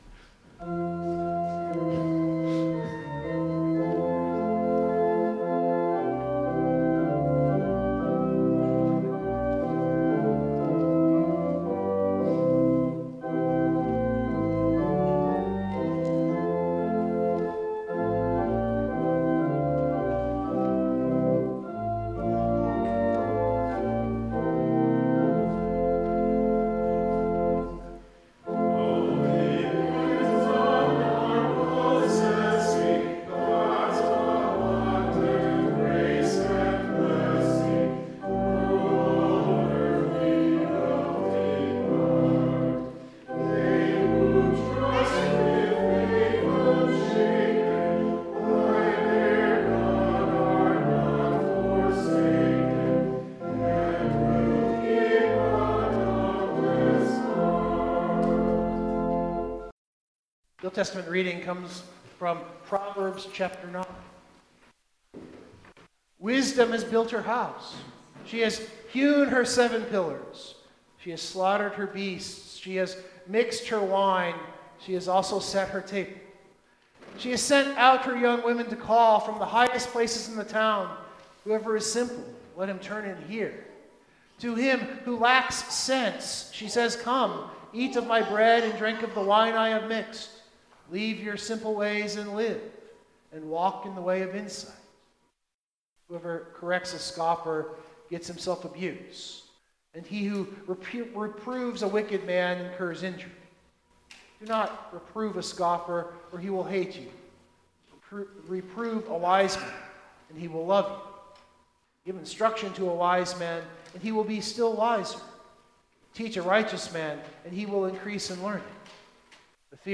Biblical Text: John 6:22-35 Full Sermon Draft